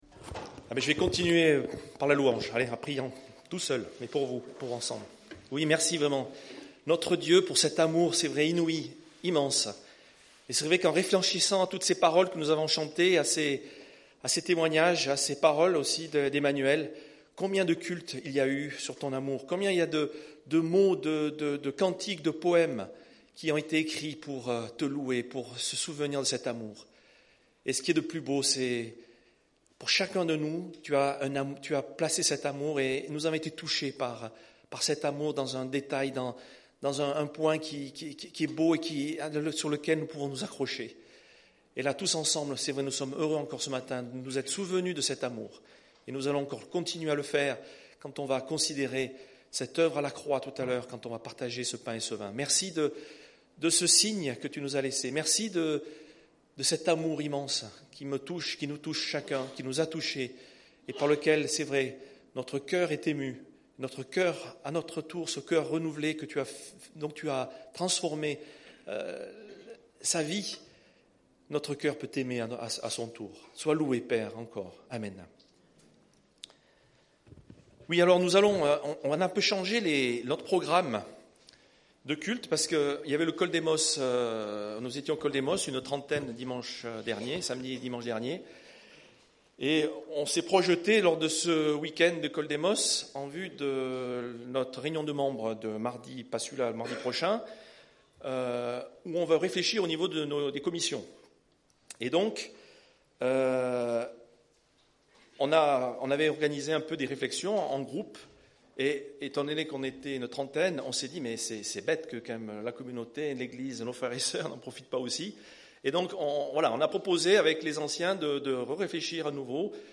Preacher: Conseil d'anciens | Series: